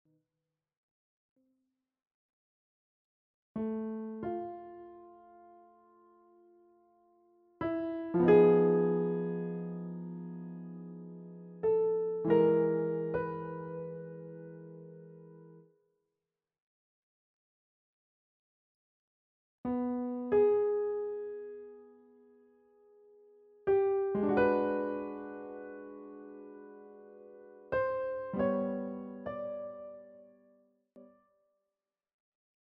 CD quality digital audio Mp3 file
using the stereo sa1mpled sound of a Yamaha Grand Piano.